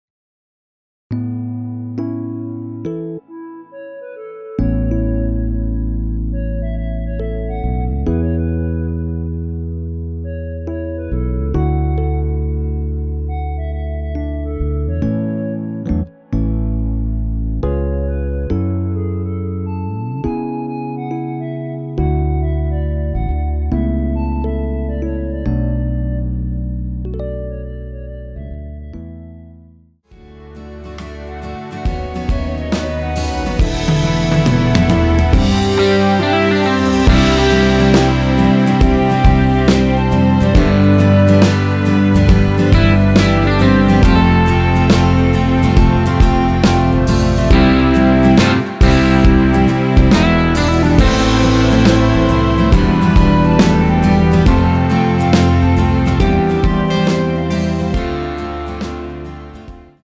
전주 없이 시작 하는 곡이라 전주 1마디 만들어 놓았습니다.(미리듣기 참조)
원키에서(-1)내린 멜로디 포함된 MR입니다.
앞부분30초, 뒷부분30초씩 편집해서 올려 드리고 있습니다.
중간에 음이 끈어지고 다시 나오는 이유는